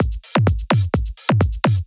次にドラムのパーカッション素材を作りました。
サンプルライブラリーを切り貼りして作成。
シャイカーだけ、サンプラーに読み込んで打ち込みしてます。